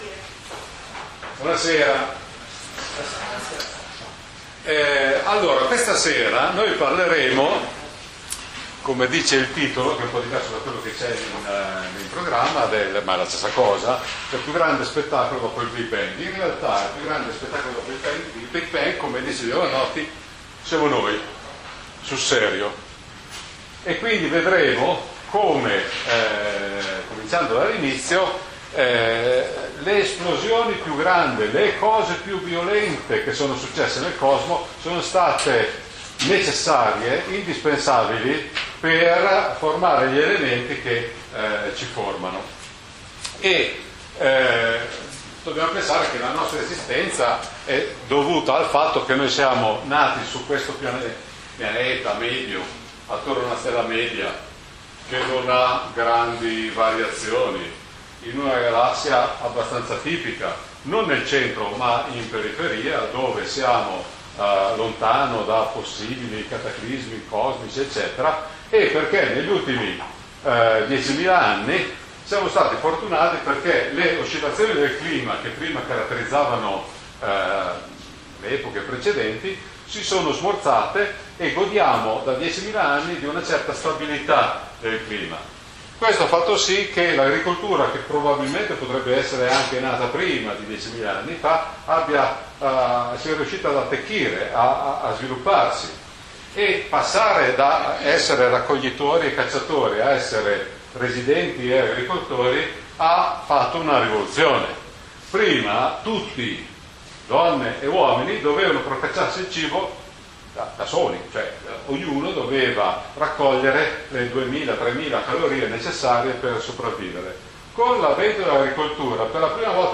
Lezioni corso base 2018-19